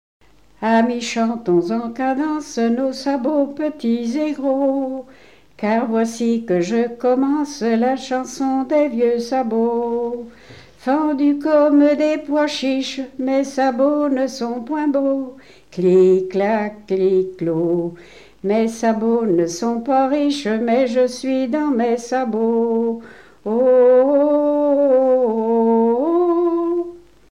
Genre strophique
Chansons de variété
Pièce musicale inédite